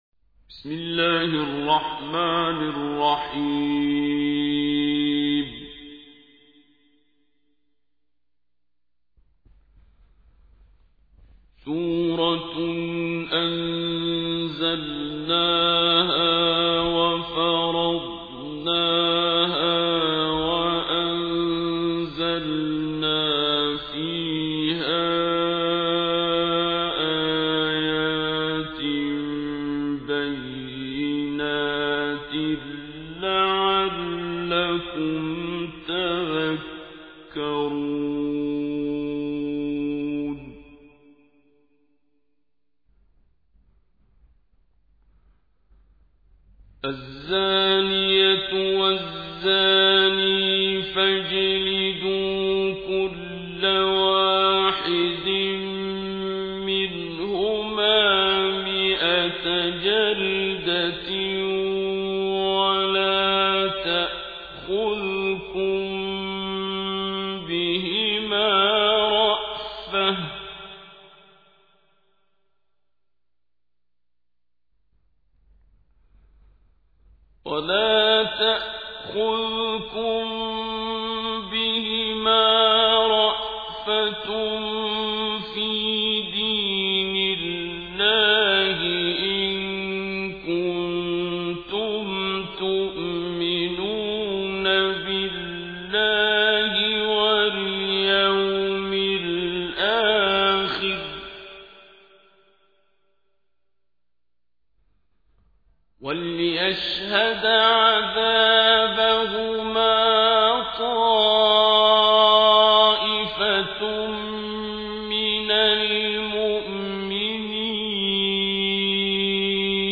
تحميل : 24. سورة النور / القارئ عبد الباسط عبد الصمد / القرآن الكريم / موقع يا حسين